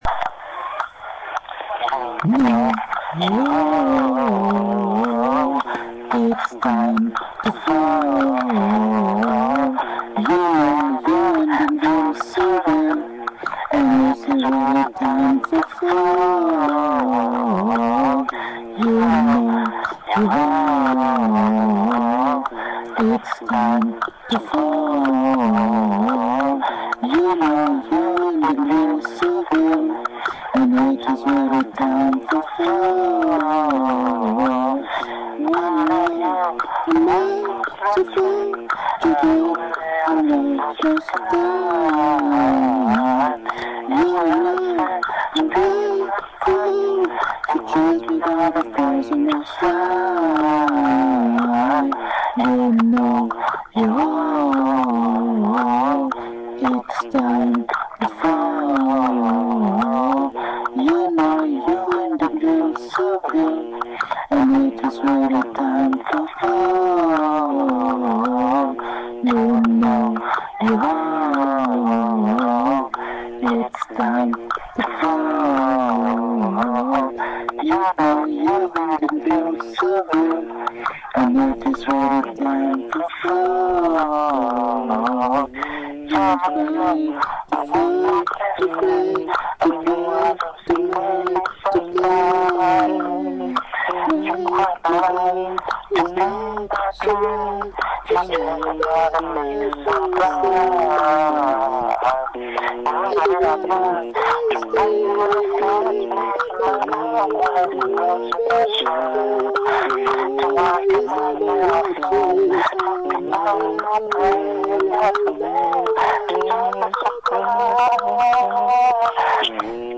une série de chanson lo-fi
instrumentalisé à la bouche ayant pour théme